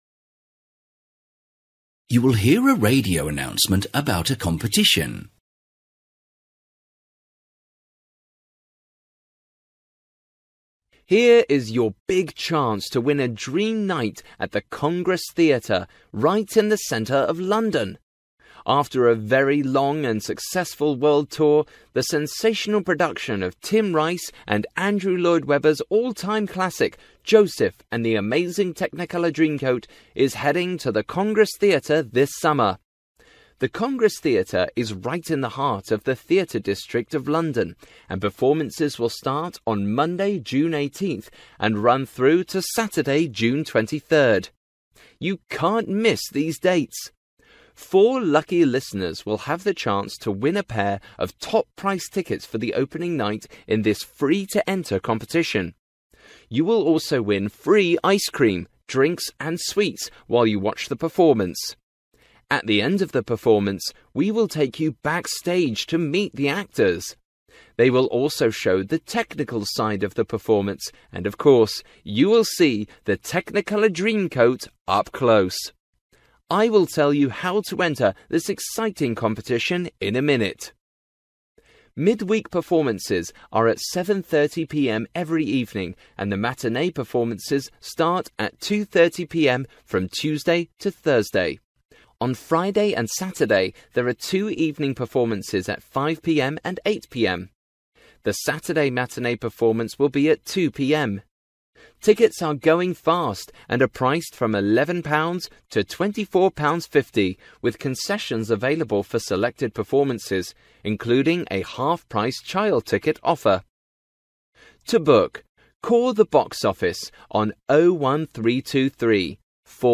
You will hear a radio announcement about a competition.